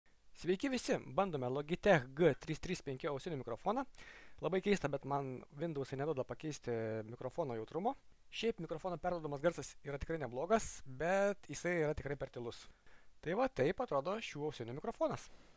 Mikrofonas
„Logitech G335“ turi atlenkiamą lankstų mikrofoną, tad galime jį prisilenkti arčiau burnos. To tikrai reikės, nes „Windows“ man neleido reguliuoti jautrumo, bet pats mikrofono perteikiamas garsas yra visai geras, tik per tylus.
Logitech-G335-mic.mp3